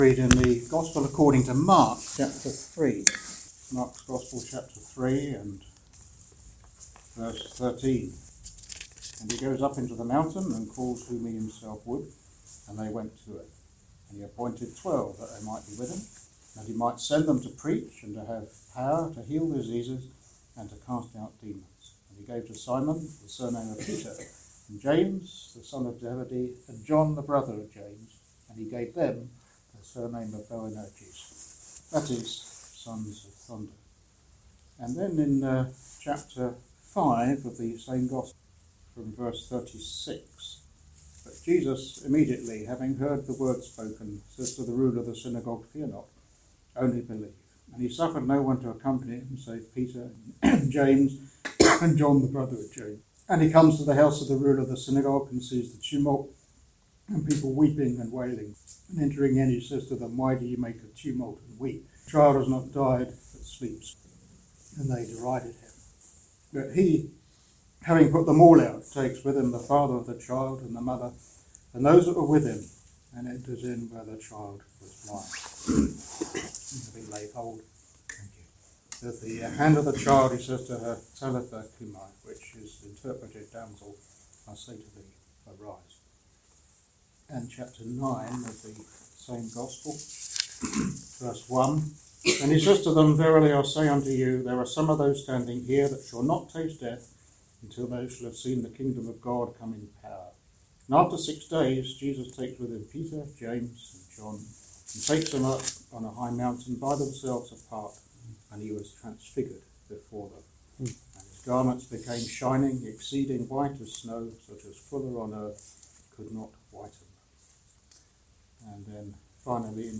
In this ministry word you will hear of 3 people who were singled out for service.